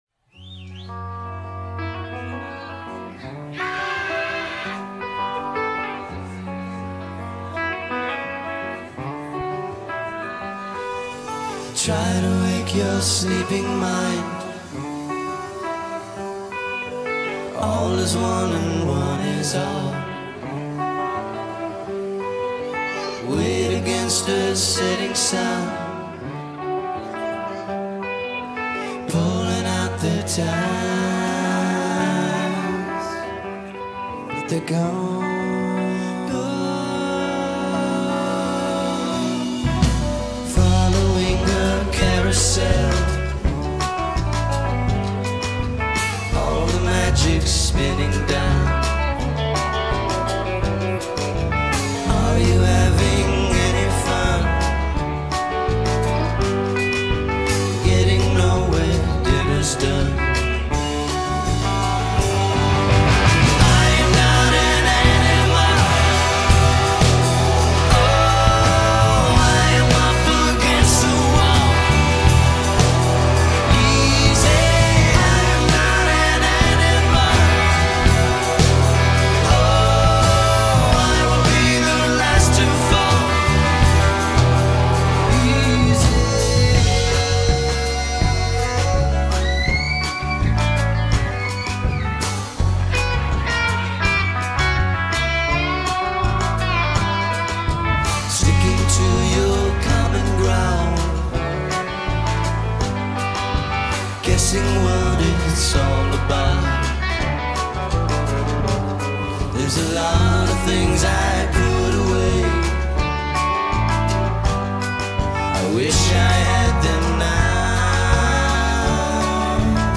Record Release Party